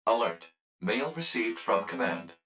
042_Mail_Notification2.wav